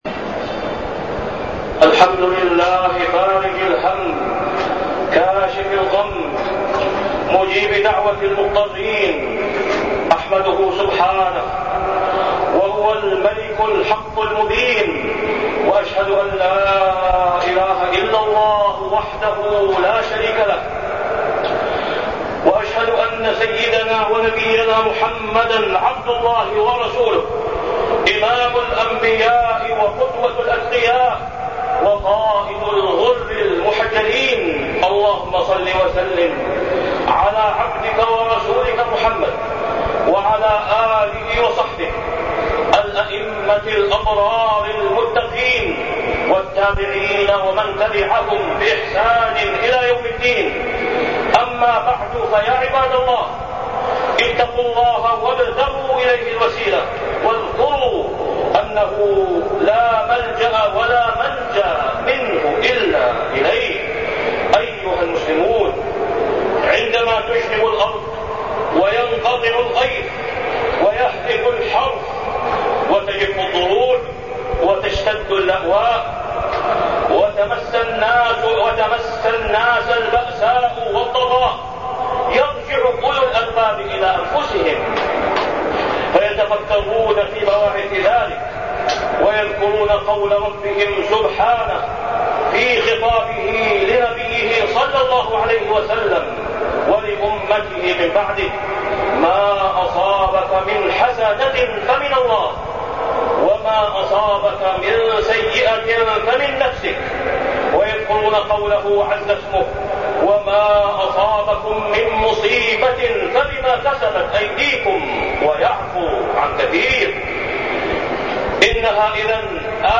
تاريخ النشر ١٥ ذو القعدة ١٤٢٤ هـ المكان: المسجد الحرام الشيخ: فضيلة الشيخ د. أسامة بن عبدالله خياط فضيلة الشيخ د. أسامة بن عبدالله خياط المعاصي